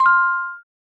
button_sound.wav